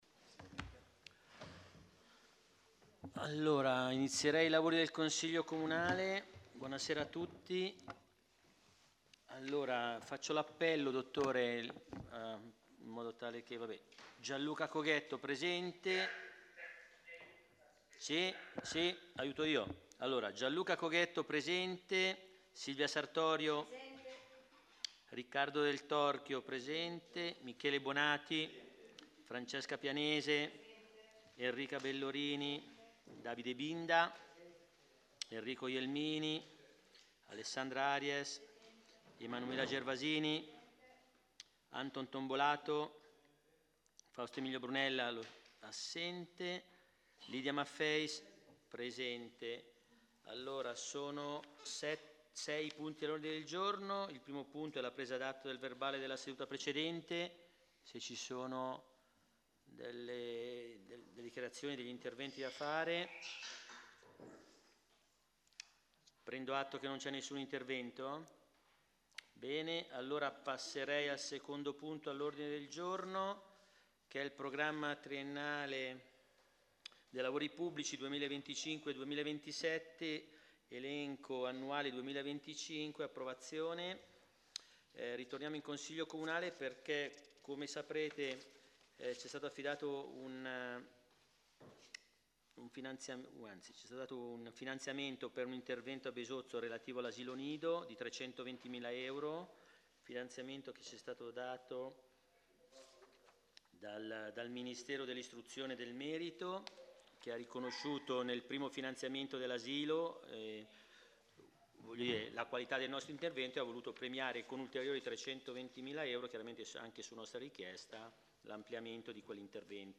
Registrazione Consiglio Comunale del 29.09.2025